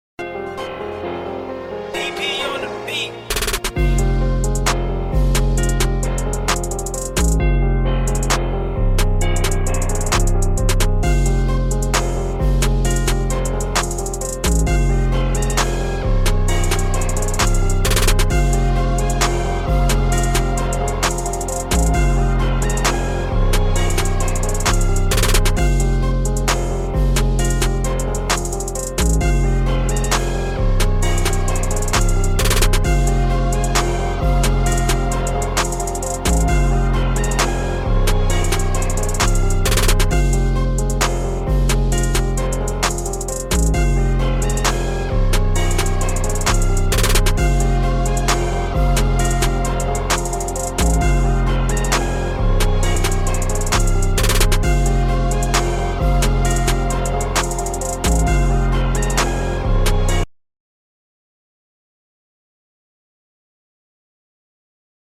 Drill Instrumental